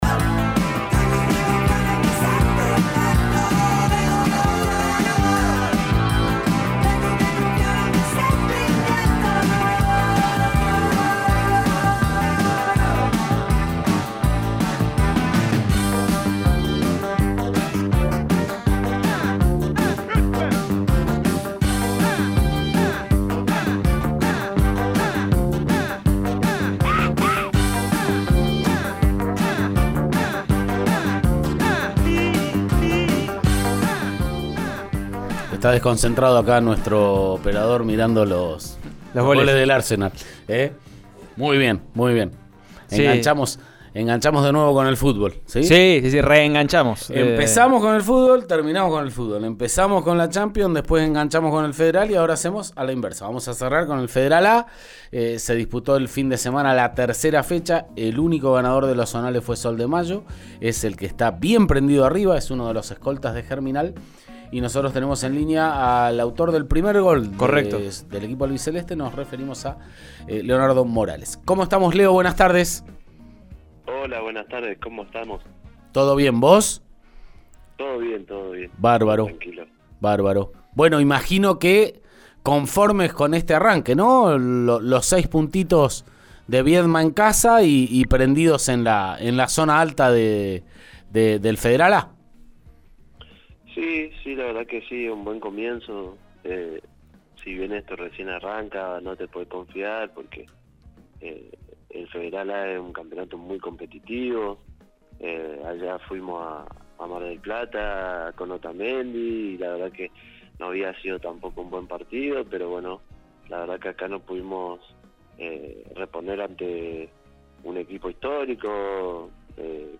en diálogo con «Subite al Podio» de RN Radio